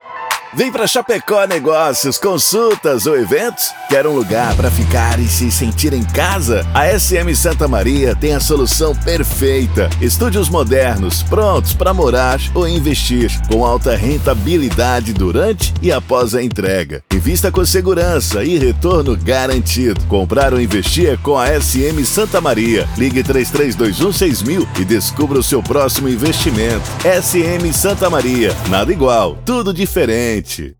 Spot SM Santa Maria: